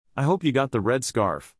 Use rising intonation on specific words in a sentence to emphasize their importance.